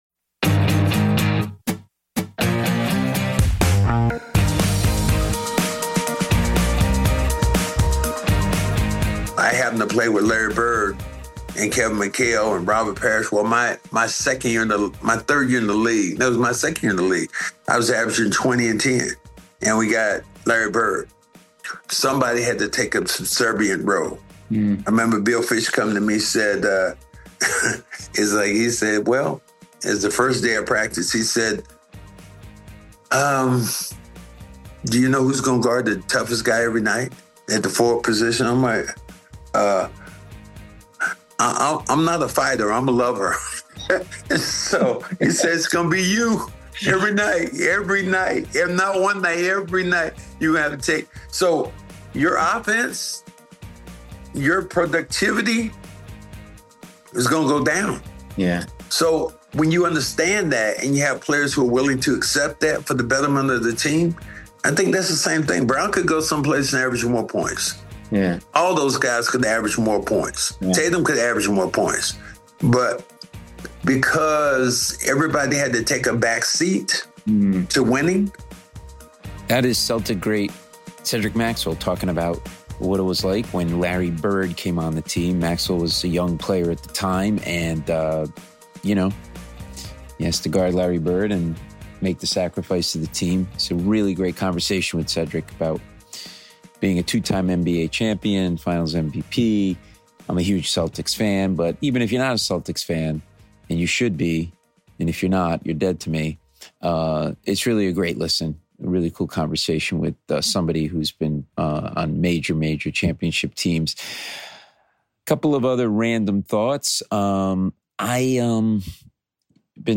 Hear first hand from an NBA champion and Finals MVP what it takes to win the biggest prize. Cedric is engrossing as he describes the nitty gritty of what made the Celts Championship run to their 18th banner so magical and what it takes to be an M+NBA champion.